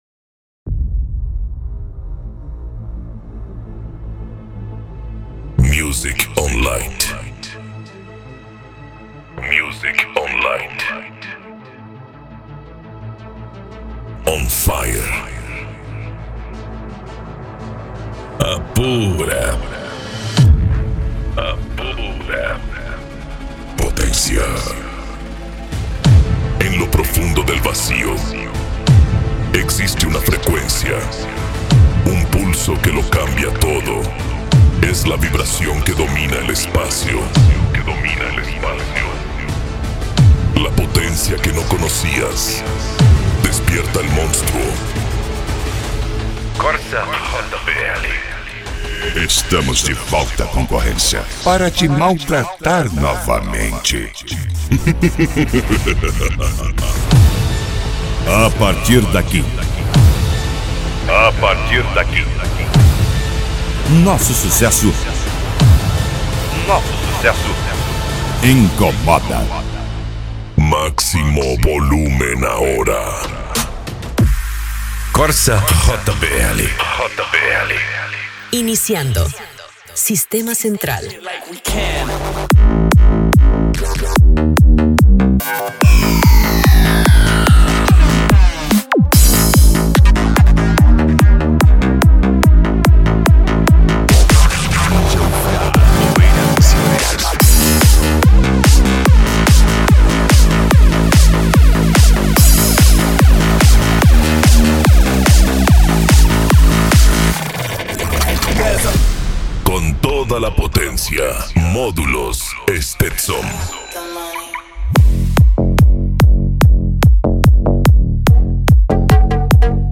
Eletronica
PANCADÃO
Psy Trance